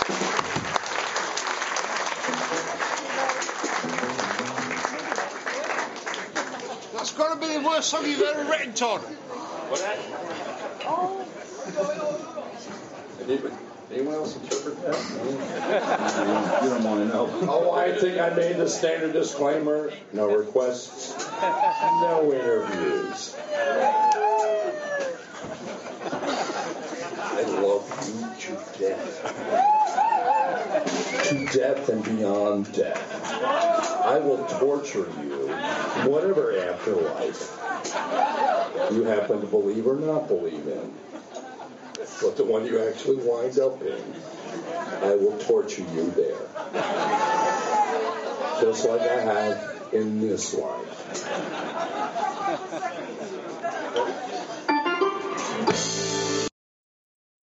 guitar, vocals
keyboards, vocals
bass, vocals
drums